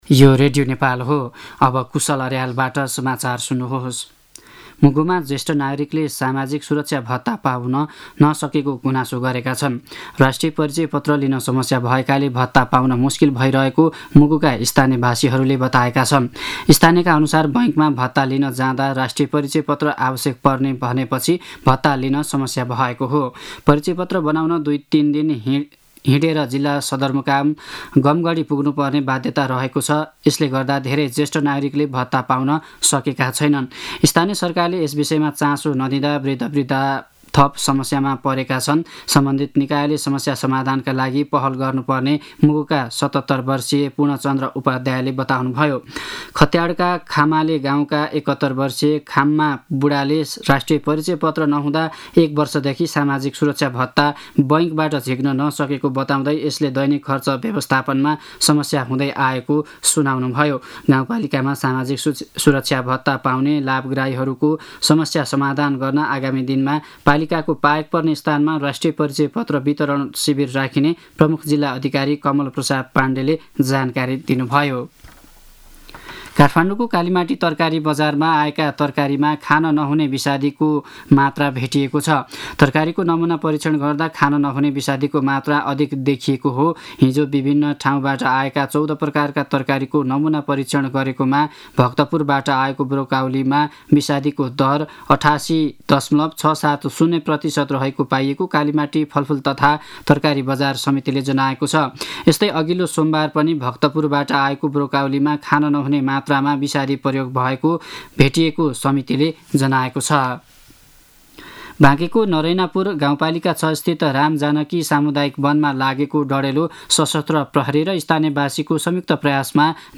दिउँसो १ बजेको नेपाली समाचार : १२ चैत , २०८१
1-pm-news-1-9.mp3